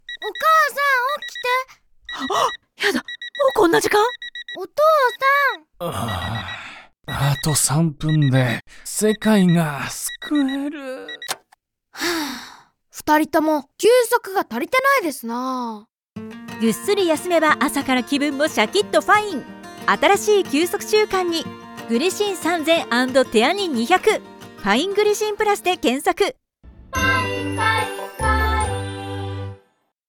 栄養補助食品「ファイングリシン+」の認知拡大と、商品理解を目的とした音声CM。
忙しい現代人の「休息不足」という課題に対し、「朝の寝坊」や「家族のドタバタ劇」といった日常の失敗談をコミカルに描き、リスナーの共感を醸成。